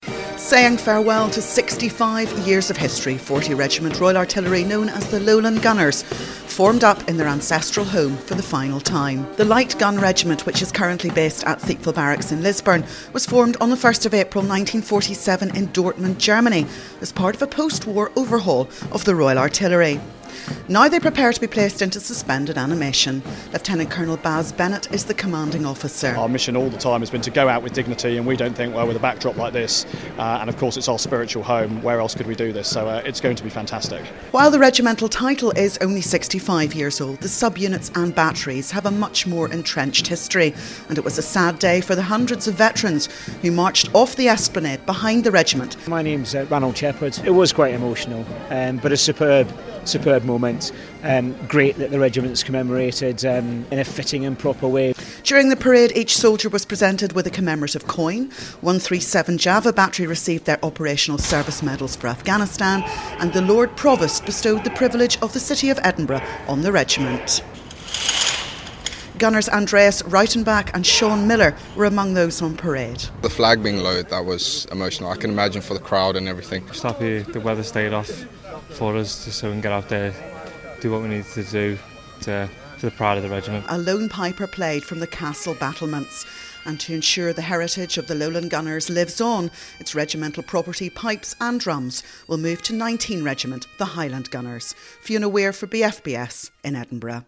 Soldiers of 40 Regiment Royal Artillery and hundreds of veterans paraded on the esplanade at Edinburgh Castle for the last time on Friday evening. As a result of the Strategic Defence and Security Review the unit is being placed into suspended animation